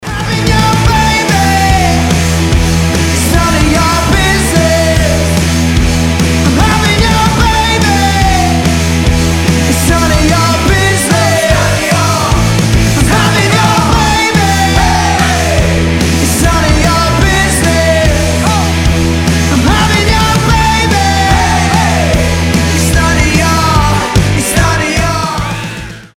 • Качество: 320, Stereo
мужской вокал
громкие
Alternative Rock
бодрые
Brit Pop
британский рок
Бодрый британский инди-рок